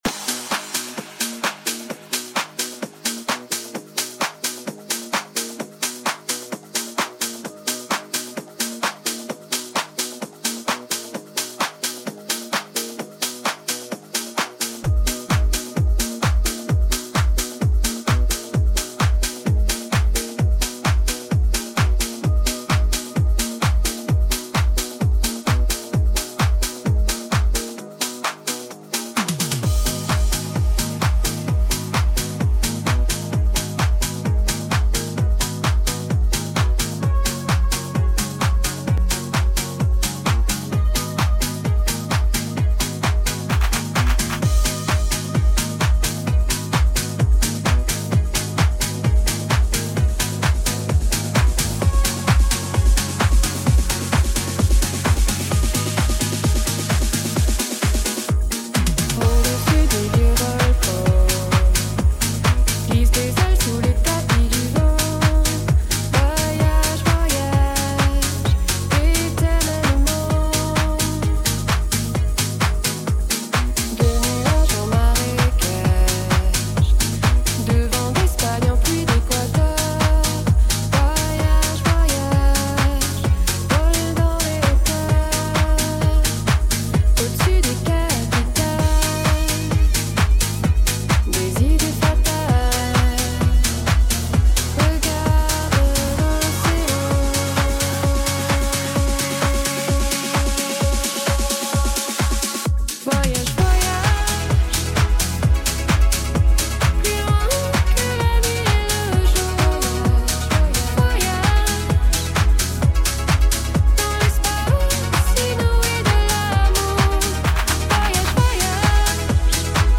upbeat music